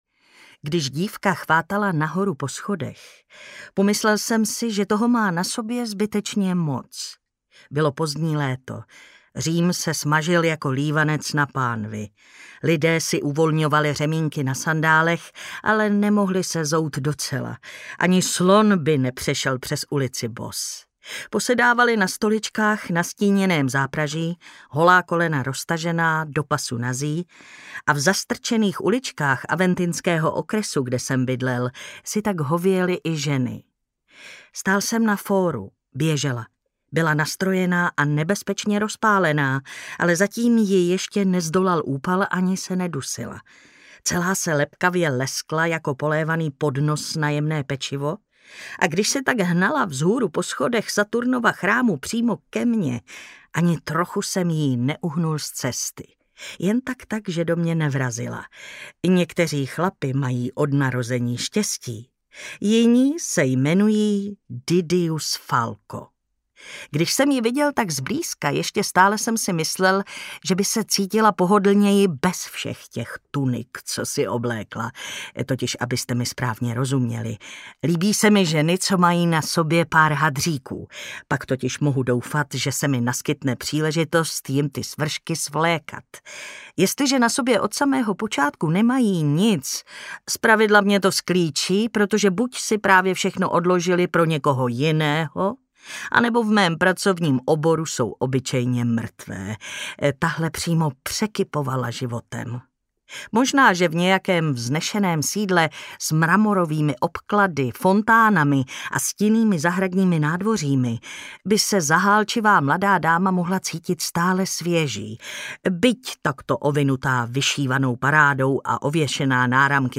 Ztracené stříbro audiokniha
Ukázka z knihy
• InterpretMartina Hudečková
ztracene-stribro-audiokniha